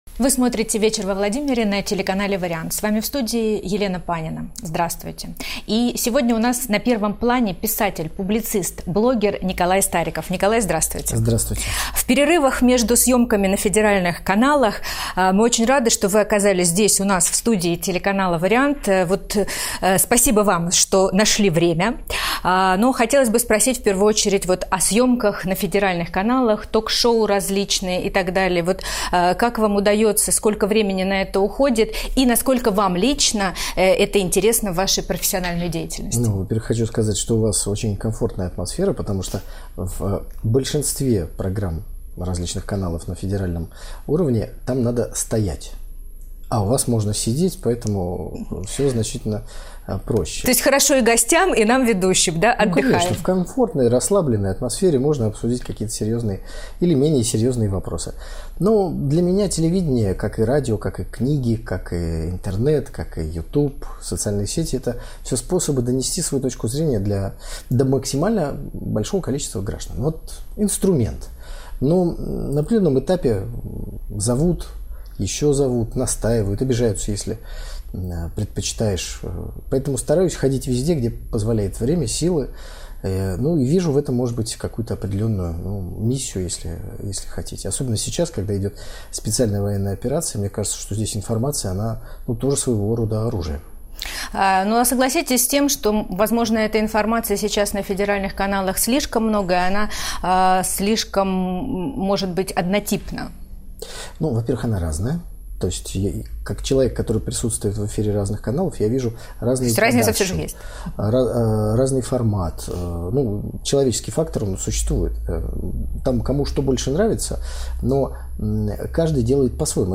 В ходе поездки во Владимир был приглашен на один из местных телеканалов, где с удовольствием ответил на вопросы ведущей.